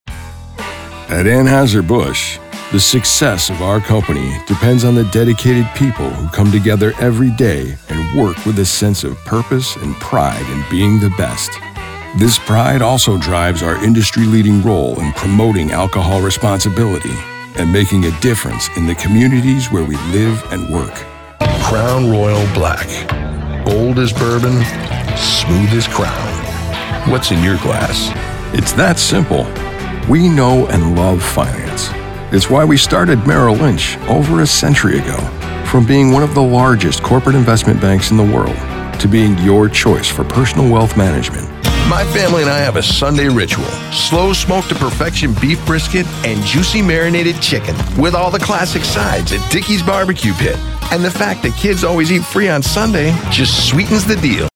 SOURCE-CONNECT Certified US MALE VOICOVER with HOME STUDIO
• BOOTH: Whisper Room, acoustically-treated
husky
MIX8-husky.mp3